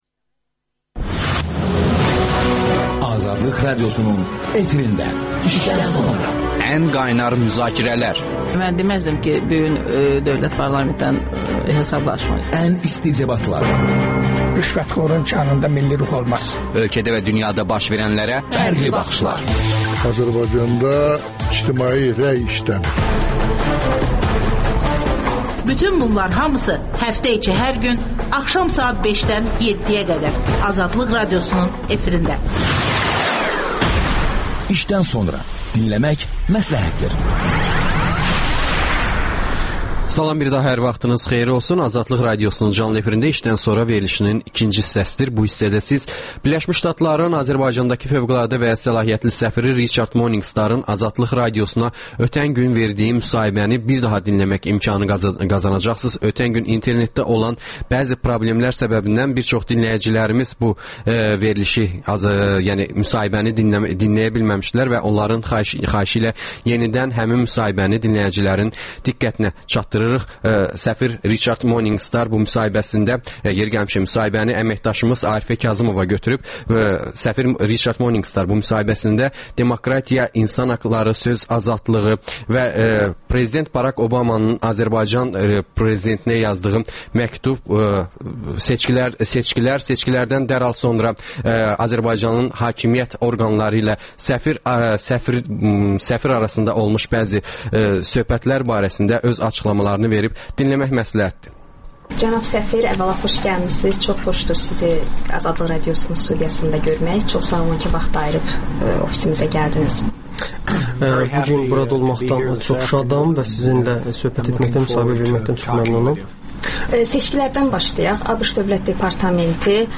İşdən sonra - ABŞ səfiri Riçard Morninqstarın AzadlıqRadiosuna müsahibəsi